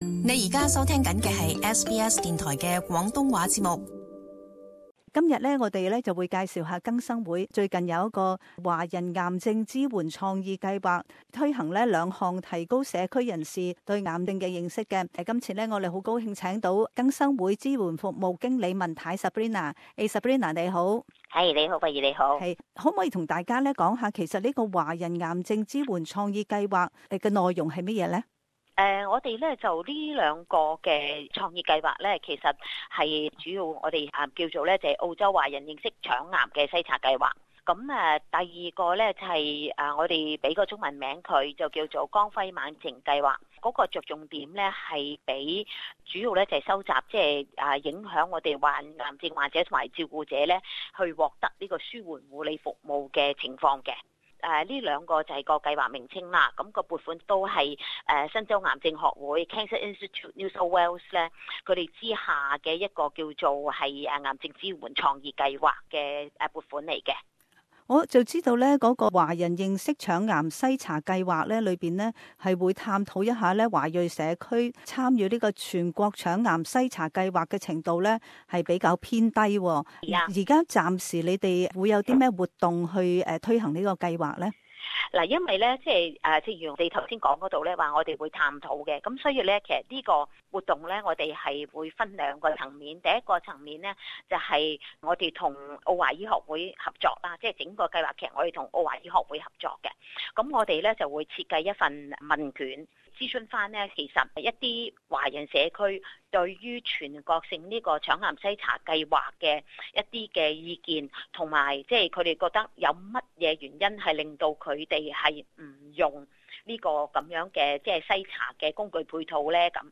Community interview: CanRevivee